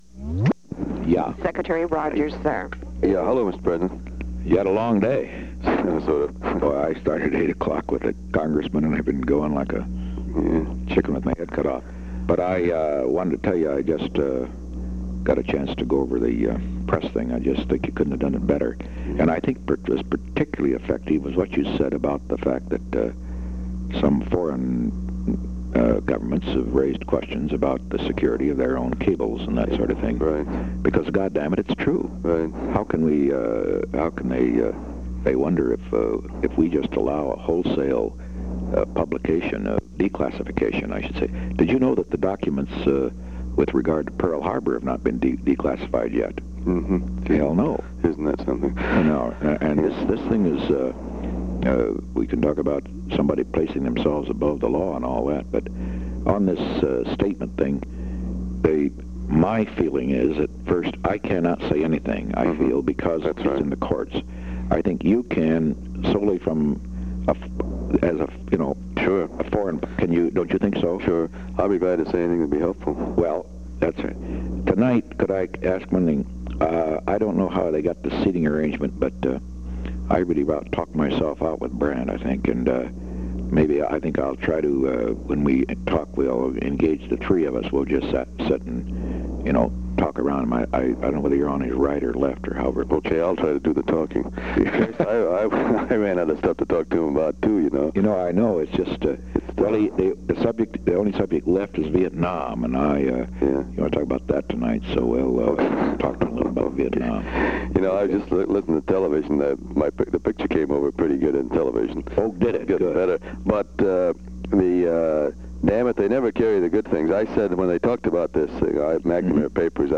Location: White House Telephone
The President talked with William Rogers.